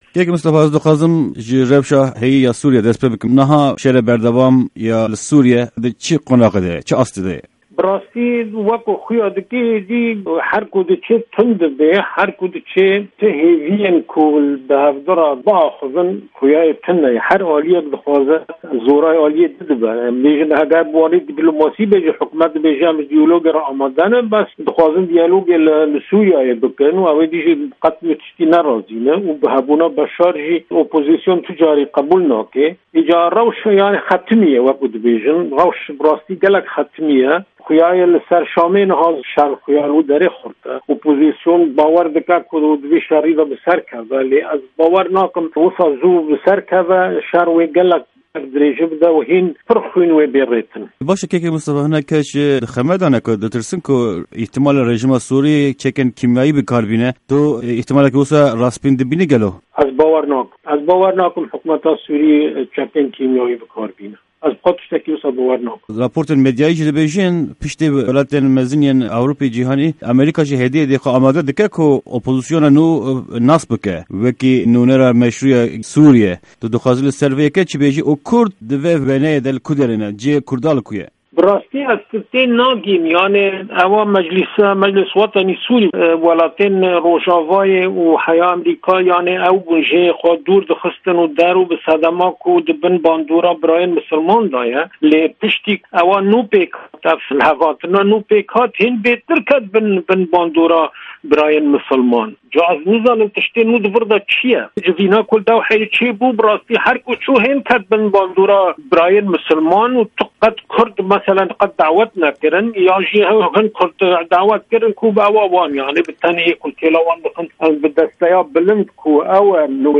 hevpeyvîna Pişka Kurdî ya Dengê Amerîka